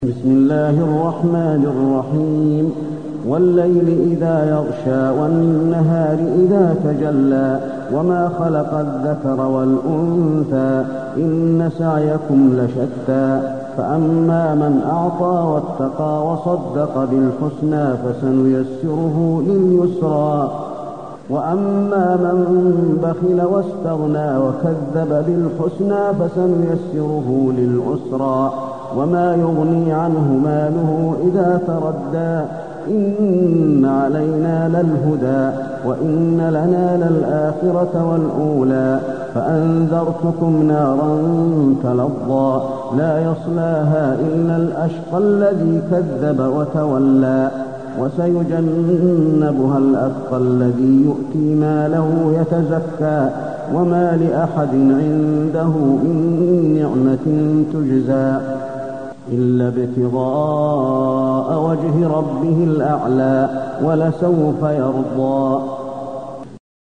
المكان: المسجد النبوي الليل